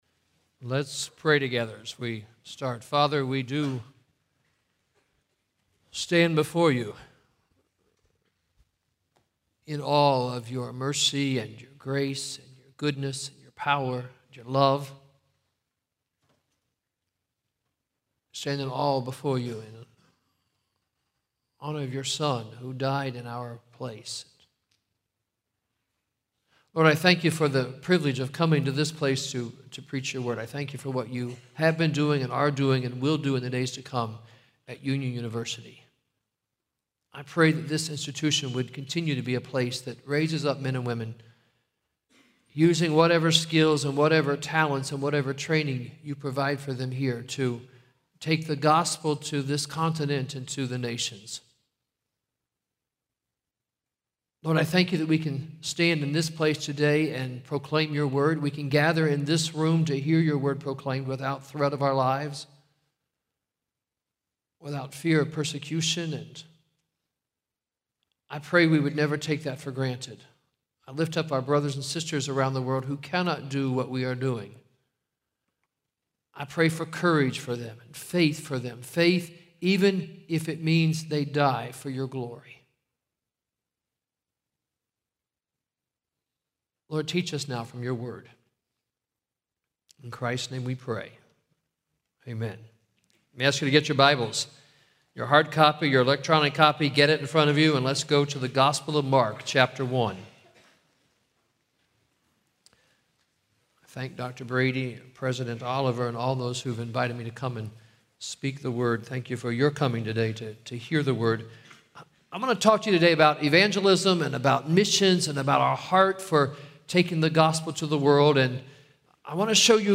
Address: "What are You Living For?" from Mark 10:17-22 Recording Date